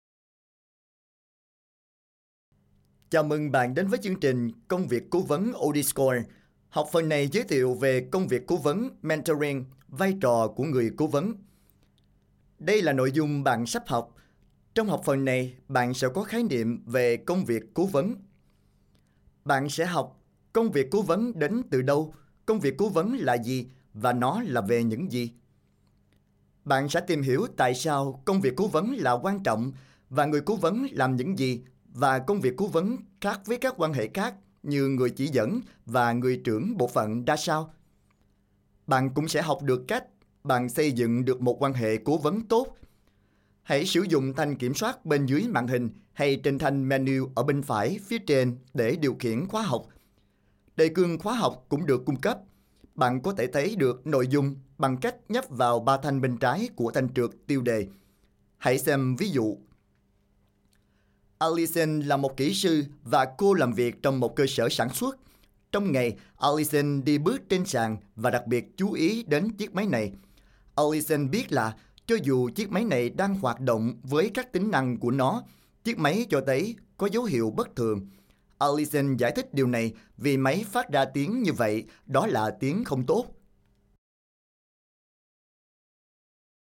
I have a rich, warm, deep and unique voice
Sprechprobe: eLearning (Muttersprache):